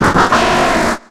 Cri de Massko dans Pokémon X et Y.